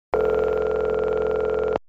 telephonerington1.mp3